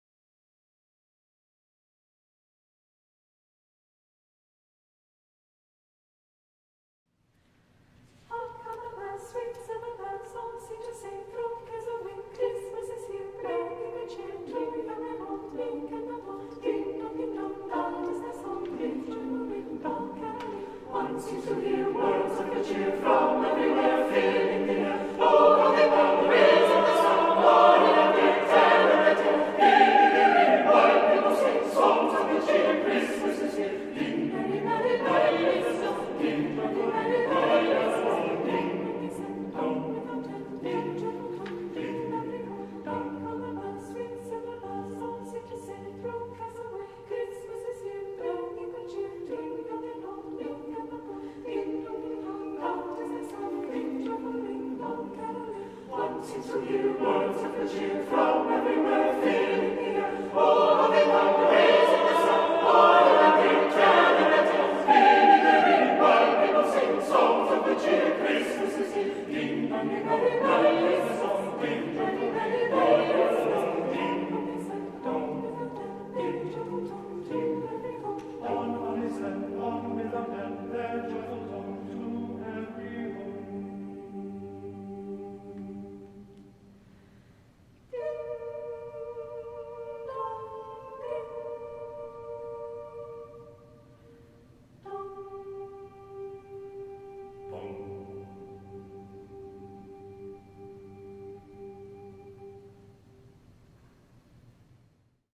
- Œuvre pour chœur à 4 voix mixtes (SATB) a capella
- Chant de noël ukrainien